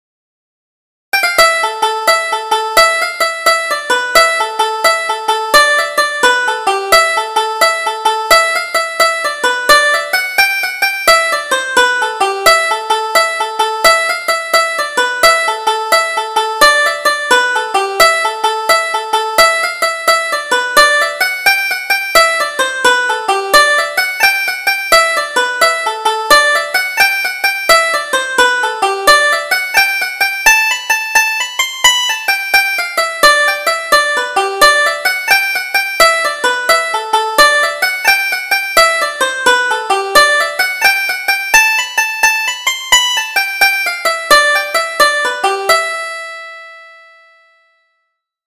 Double Jig: Wasn't She Fond of Me?